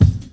• '90s Hip-Hop Kickdrum Sound G Key 38.wav
Royality free steel kick drum sound tuned to the G note. Loudest frequency: 181Hz